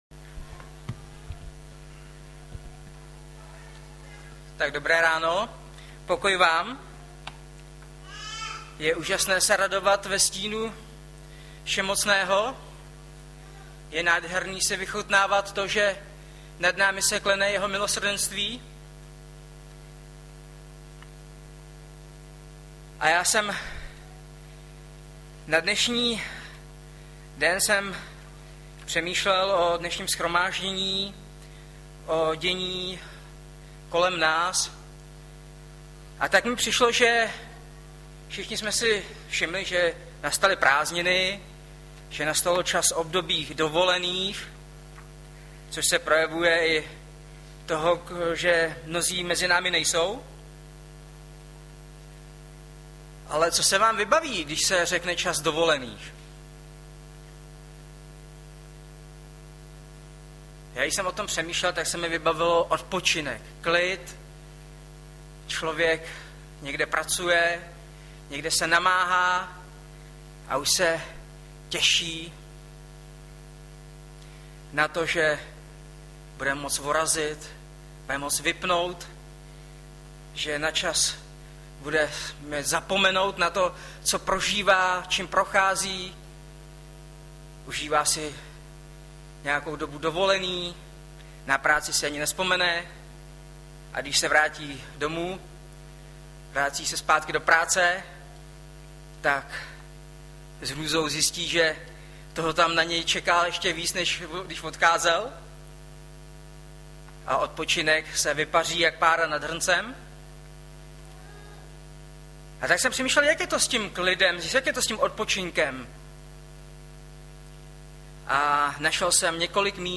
Webové stránky Sboru Bratrské jednoty v Litoměřicích.
Hlavní nabídka Kázání Chvály Kalendář Knihovna Kontakt Pro přihlášené O nás Partneři Zpravodaj Přihlásit se Zavřít Jméno Heslo Pamatuj si mě  08.07.2012 - JÁ VÁM DÁM ODPOČINOUT - Mat 11,25-30 Audiozáznam kázání si můžete také uložit do PC na tomto odkazu.